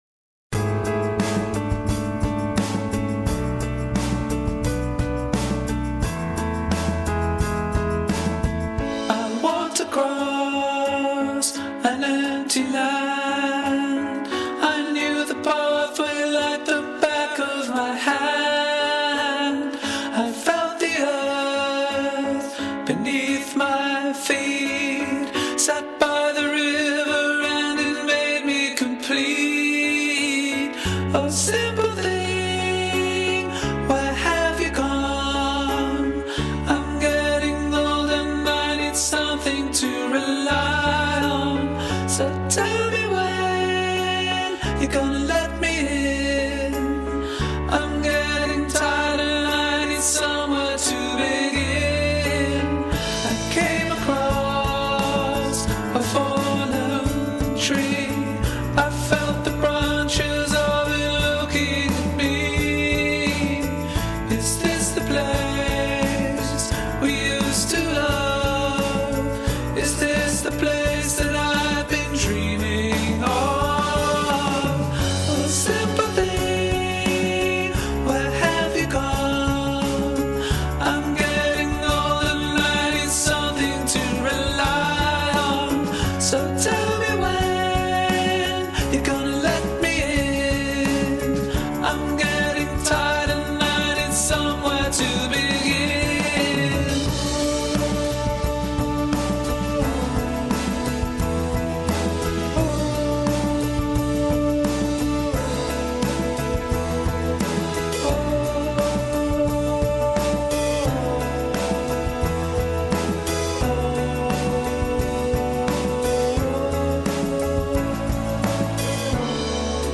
Somewhere_Only_We_Know_Tenor.mp3